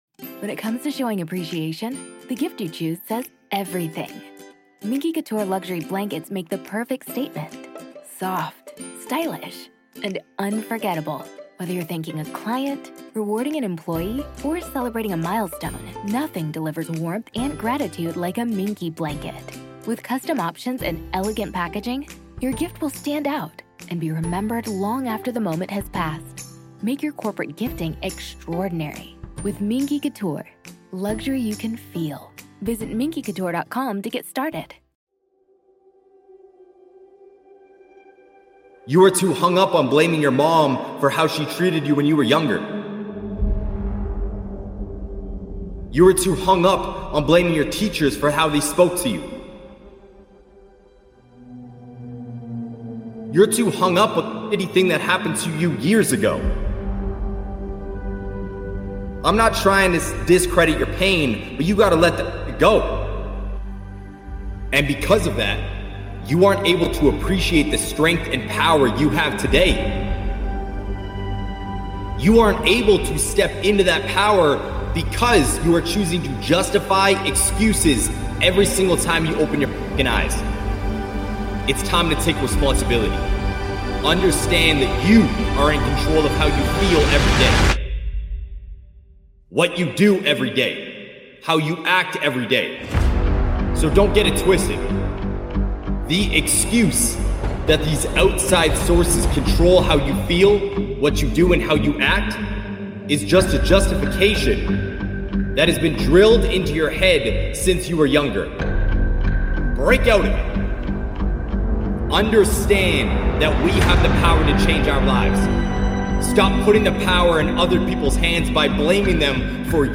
One of the Best Motivational Speeches